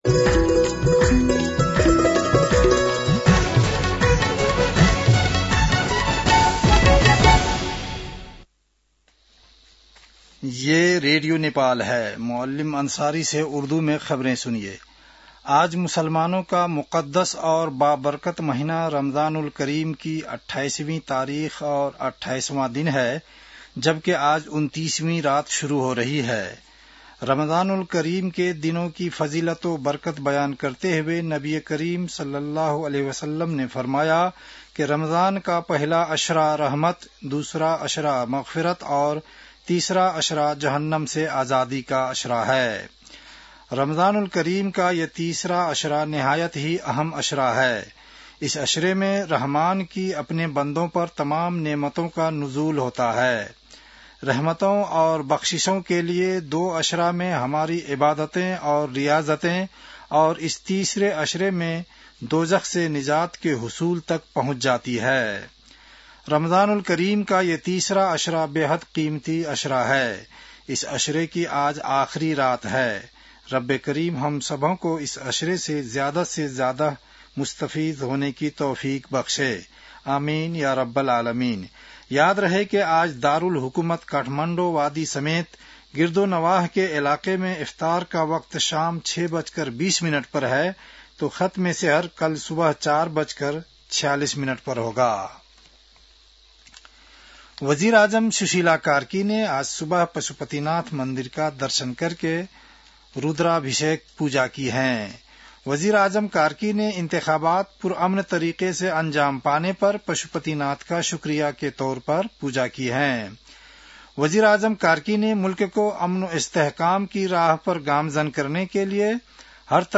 उर्दु भाषामा समाचार : ४ चैत , २०८२